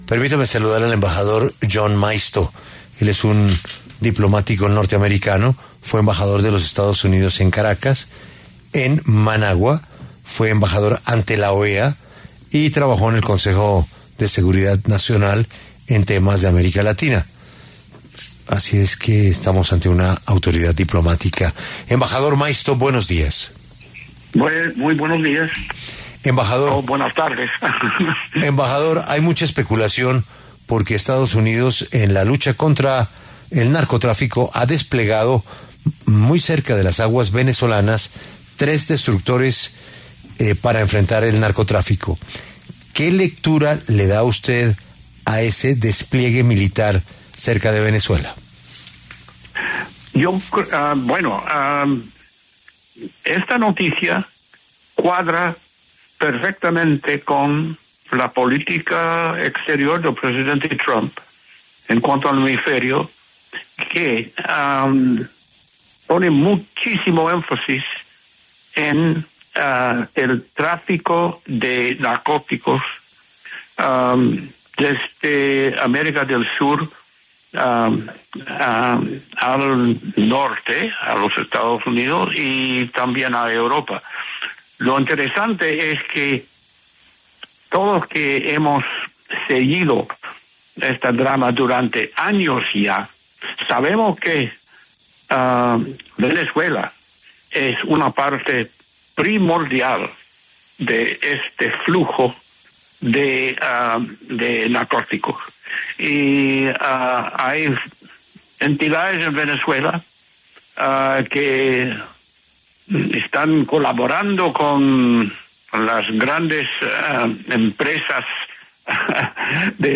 En los micrófonos de La W, con Julio Sánchez Cristo, habló John F. Maisto, un diplomático estadounidense retirado, quien se refirió al despliegue de tres destructores Aegis, por parte de Estados Unidos, cerca a Venezuela para enfrentar amenazas del narcotráfico.